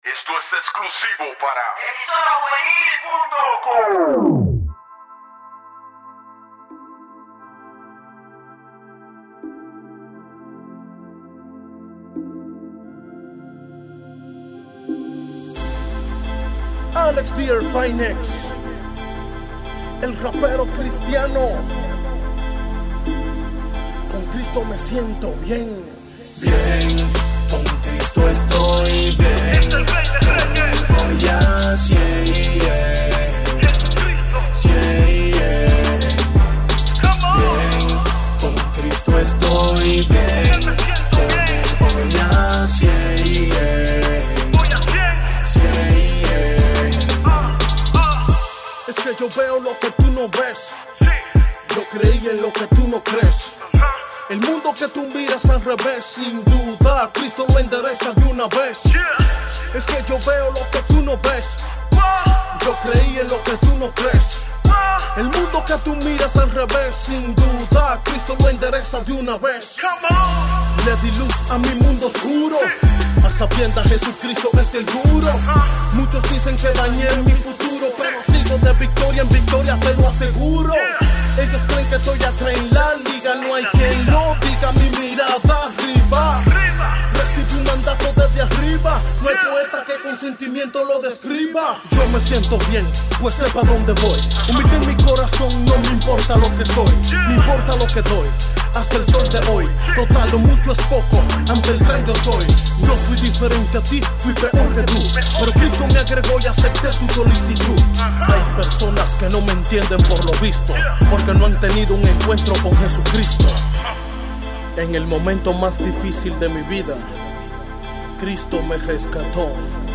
Música Cristiana
El cantante cristiano de música rap